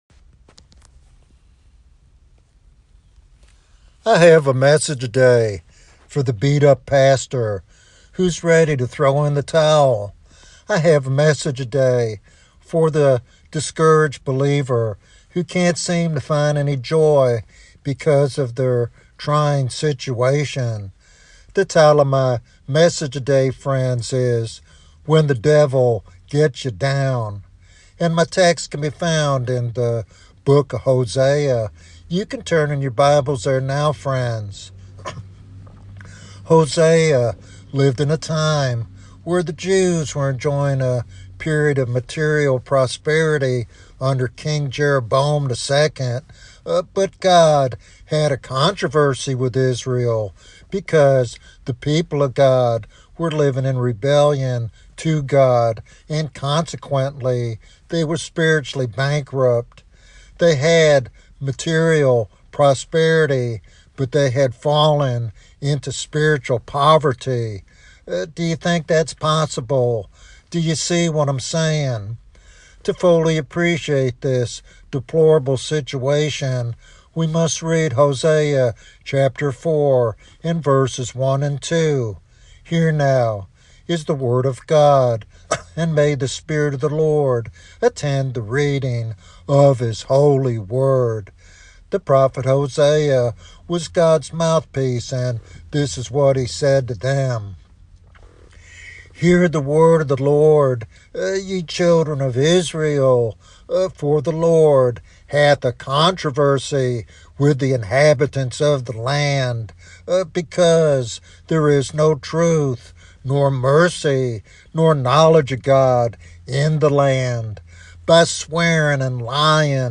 This sermon offers hope and practical guidance for those struggling to find joy and intimacy with Christ.